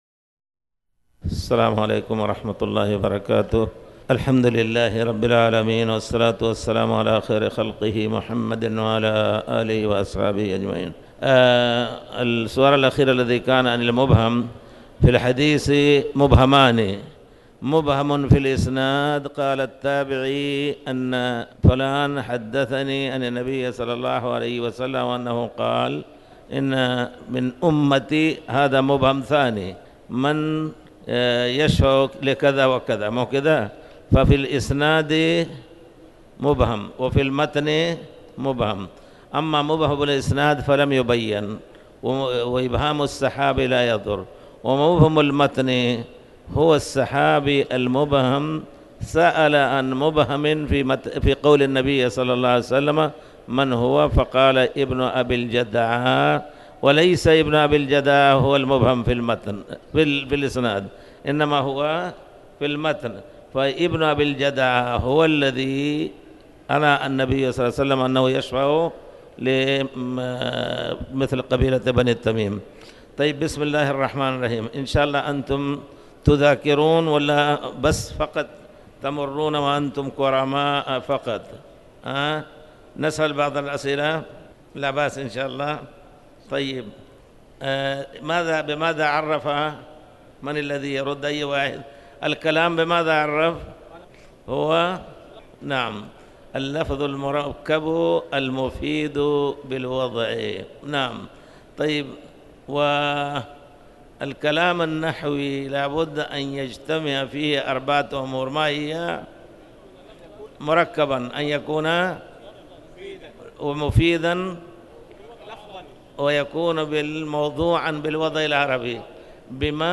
تاريخ النشر ٨ جمادى الآخرة ١٤٣٩ هـ المكان: المسجد الحرام الشيخ